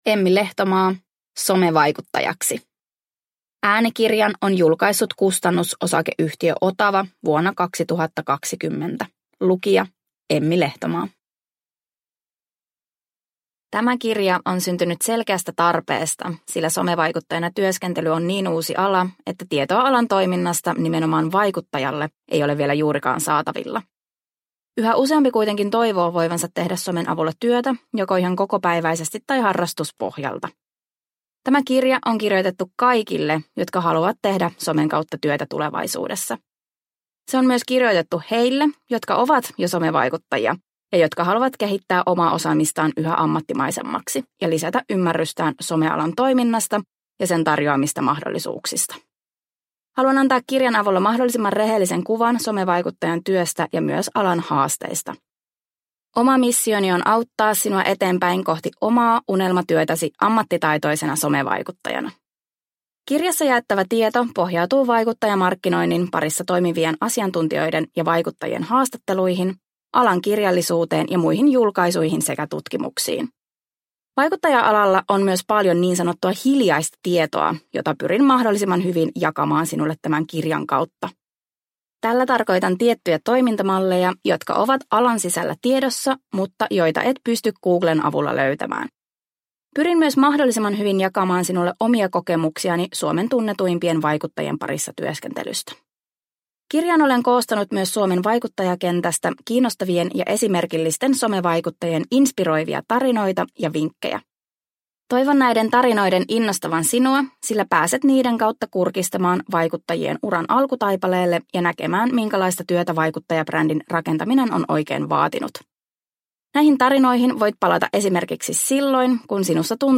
Somevaikuttajaksi! – Ljudbok – Laddas ner